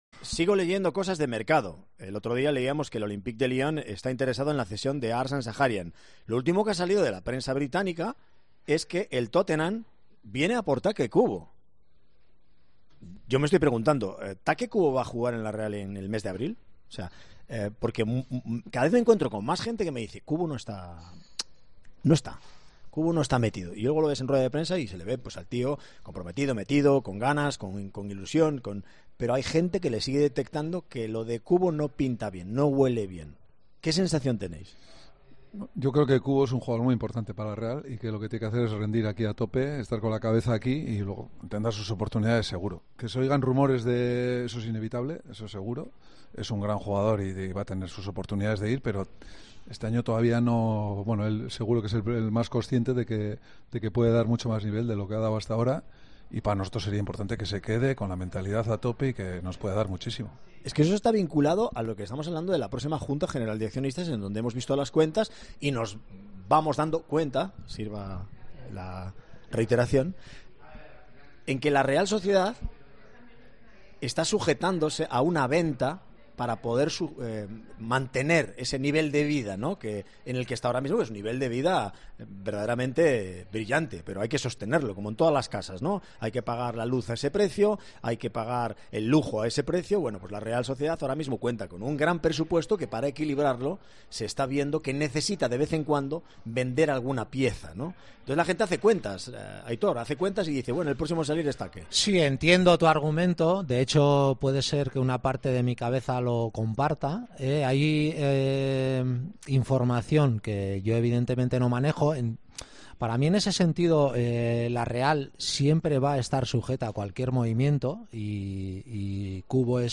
Este completo análisis sobre el futuro de Take Kubo tuvo lugar en el espacio 'El Vestuario del Kukuarri'.